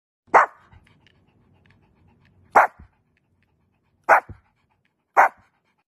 Shihtzu Dog Barking ShihTzu Sound Effects Free Download
shihtzu Dog Barking ShihTzu sound effects free download